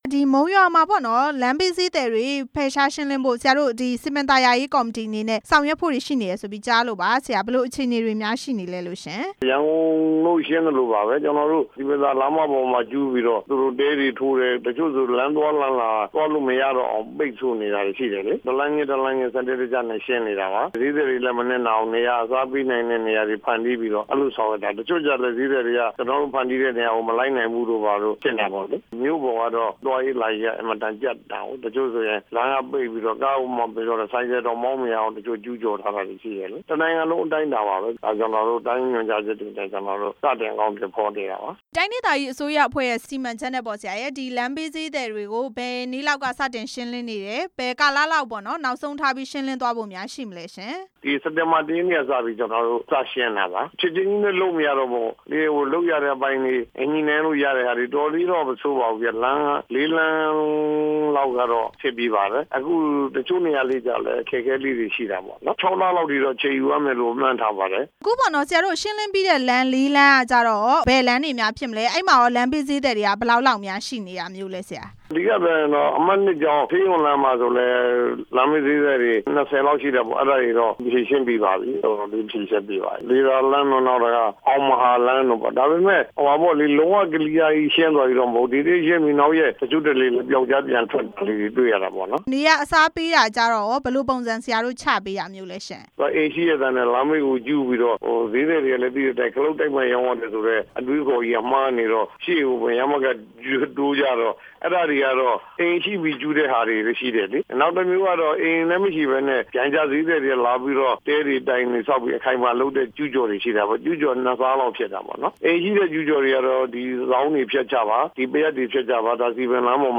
ဦးကျော်မျိုးဝင်းနဲ့ မေးမြန်းချက်